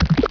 SnotPlop.wav